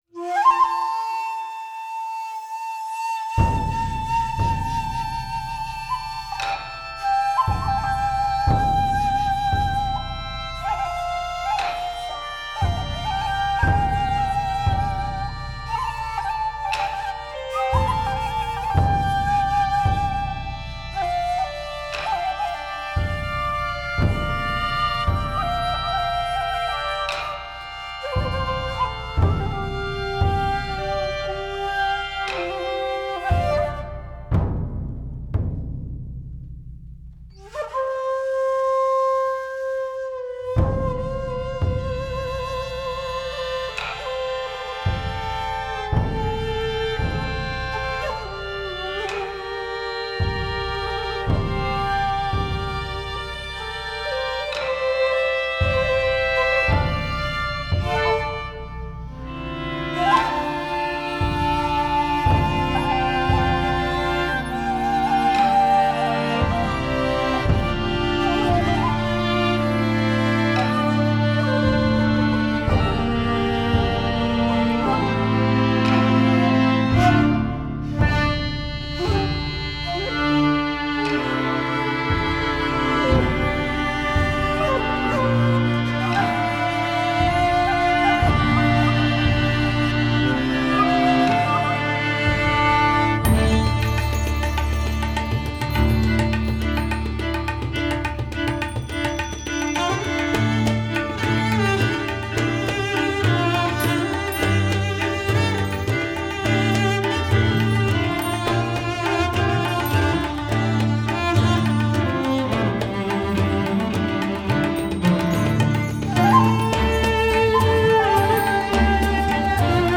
Жанр: Classical.